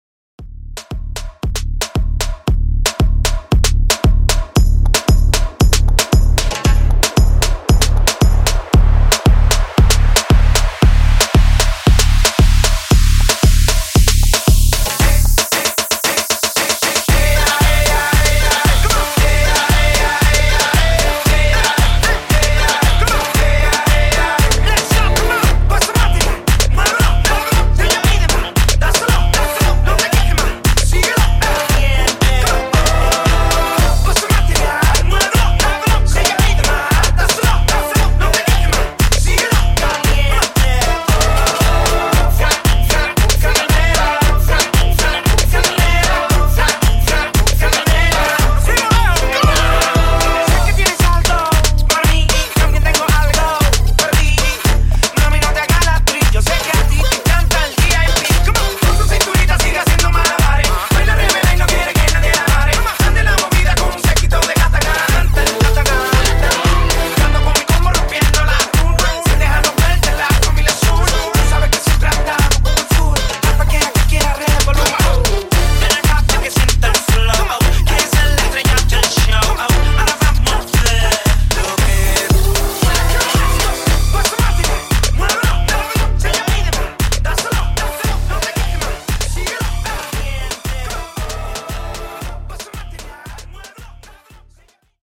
Reggaeton)Date Added